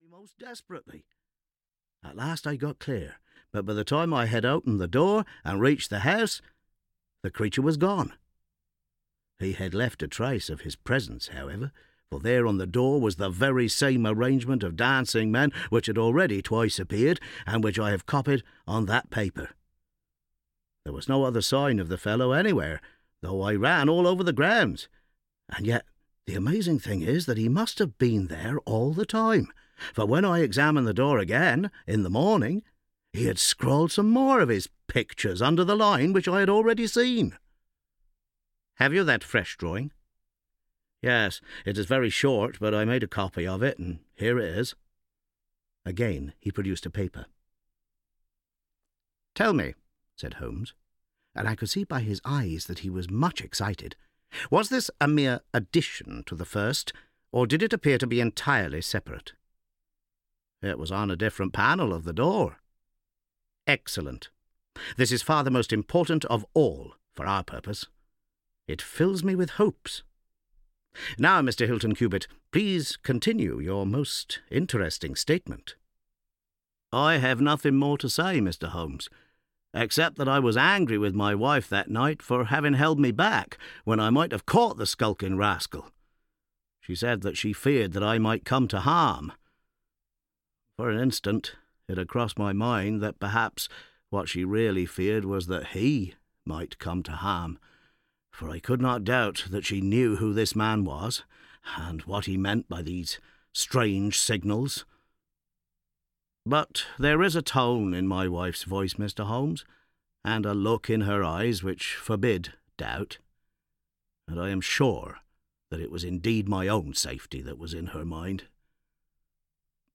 The Return of Sherlock Holmes – Volume II (EN) audiokniha
Ukázka z knihy